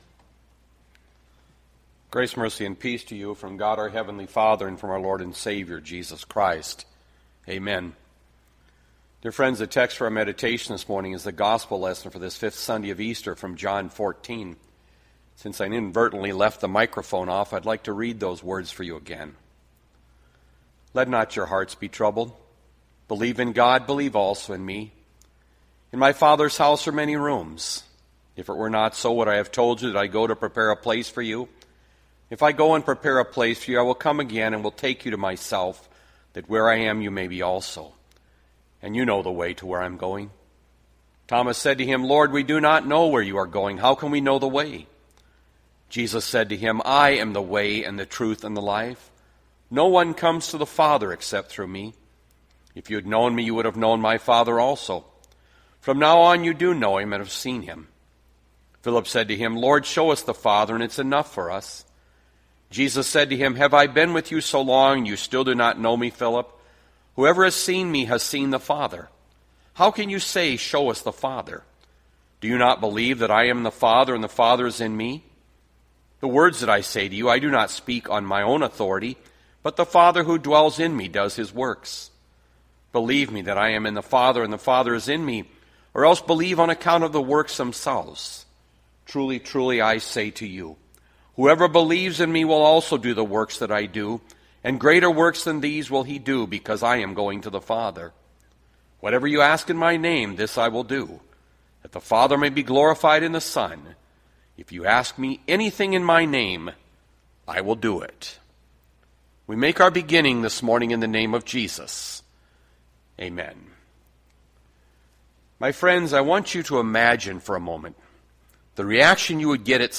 Bethlehem Lutheran Church, Mason City, Iowa - Sermon Archive May 10, 2020